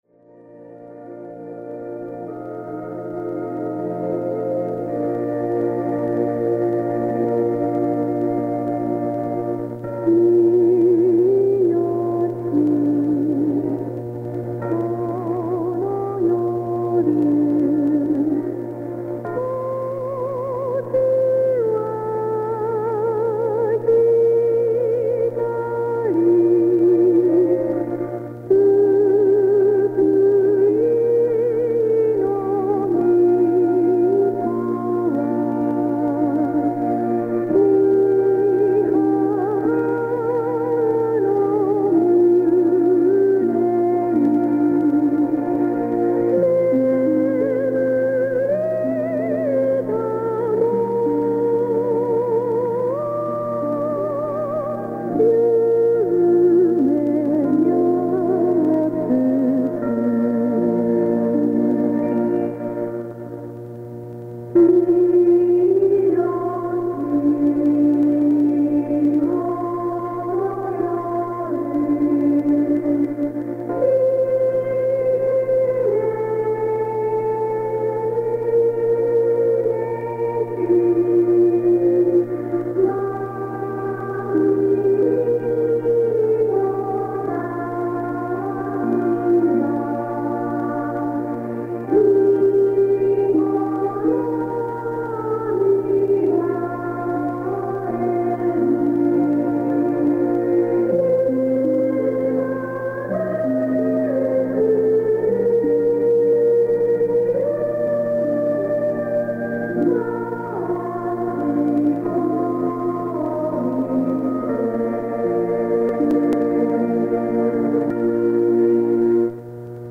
Complete Silent Night heard on Radio Japan: